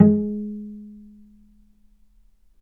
healing-soundscapes/Sound Banks/HSS_OP_Pack/Strings/cello/pizz/vc_pz-G#3-mf.AIF at 48f255e0b41e8171d9280be2389d1ef0a439d660
vc_pz-G#3-mf.AIF